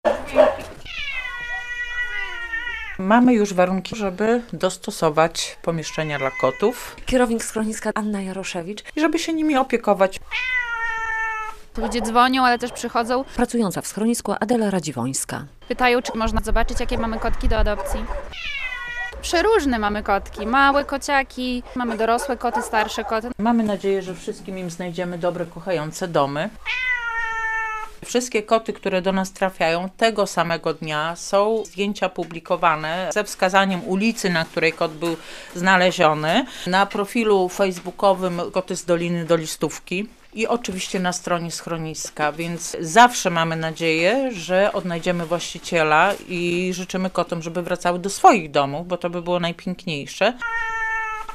W "Dolinie Dolistówki" pojawiły się koty - relacja